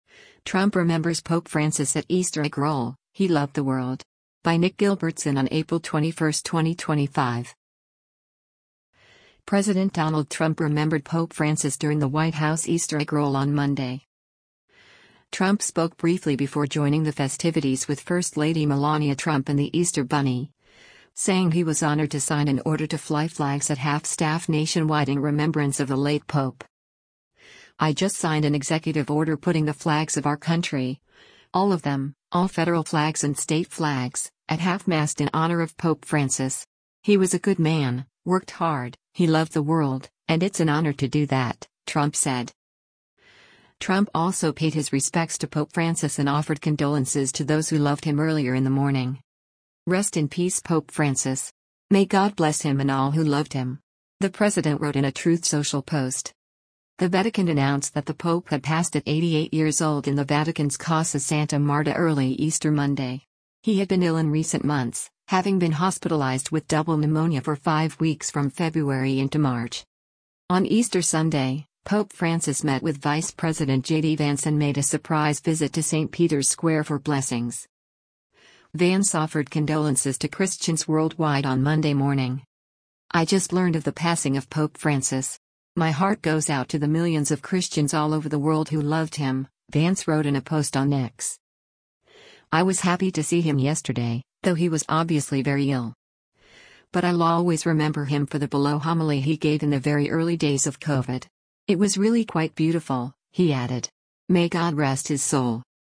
President Donald Trump remembered Pope Francis during the White House Easter Egg Roll on Monday.
Trump spoke briefly before joining the festivities with first lady Melania Trump and the Easter Bunny, saying he was honored to sign an order to fly flags at half-staff nationwide in remembrance of the late pope.